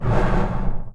MONSTER_Breath_03_Fast_mono.wav